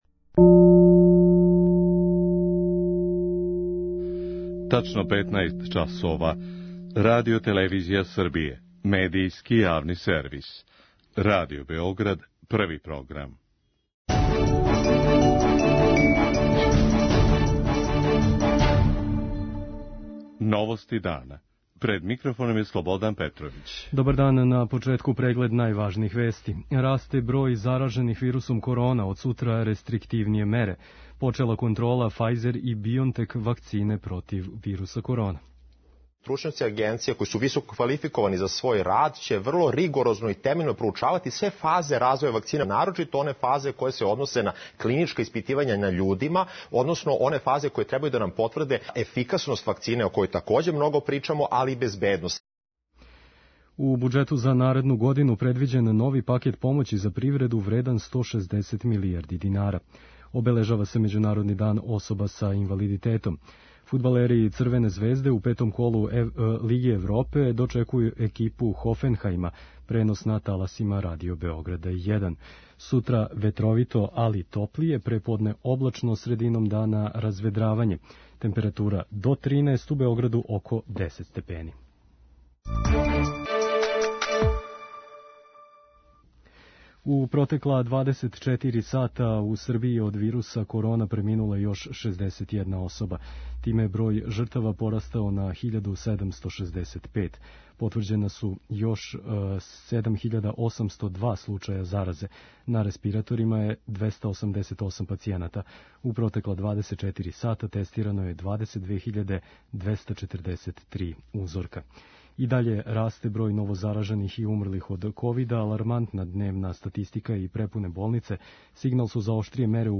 Без ограничења, биће отворене: апотеке, бензинске пумпе, као и фирме које се баве доставом хране. преузми : 6.55 MB Новости дана Autor: Радио Београд 1 “Новости дана”, централна информативна емисија Првог програма Радио Београда емитује се од јесени 1958. године.